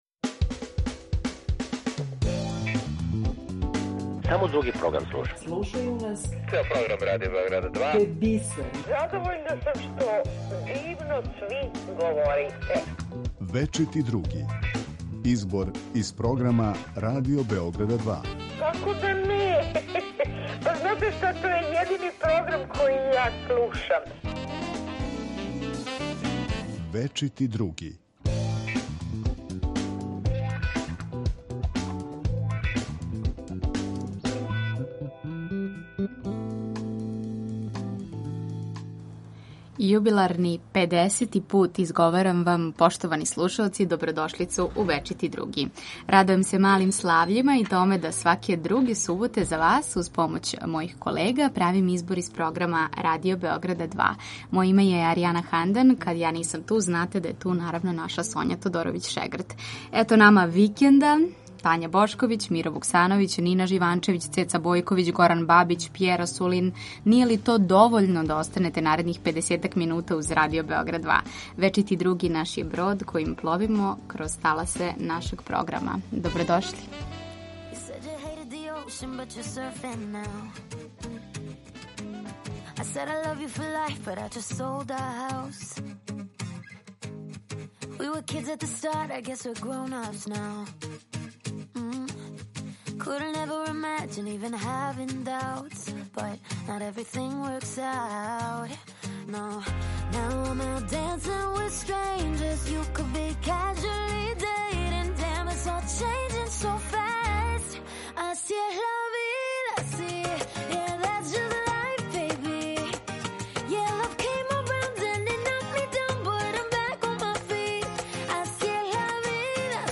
Чућемо академика и писца Мира Вуксановића. Глумица Тања Бошковић говориће о томе колико памтимо људе који су задужили уметност, културу, а самим тим и нацију. О књизи „Повратак у Сефарад" говориће познати француски писац Пјер Асулин.